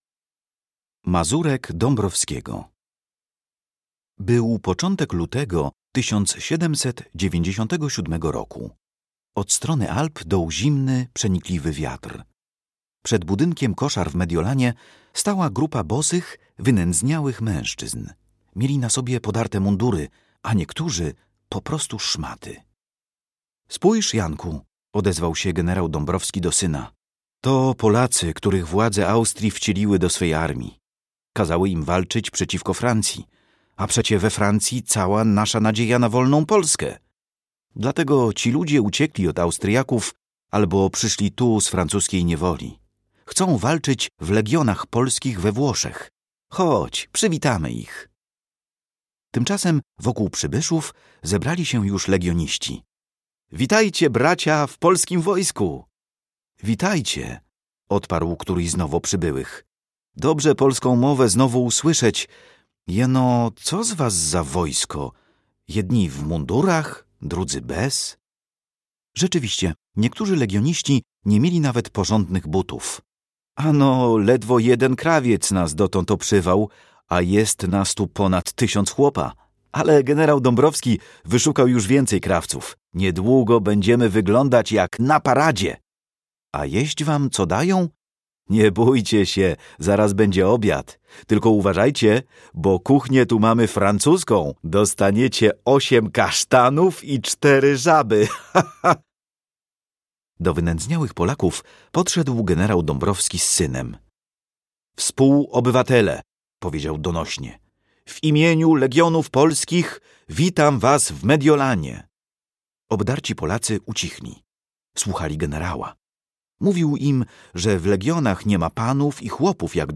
Słuchowiska. Klasa 6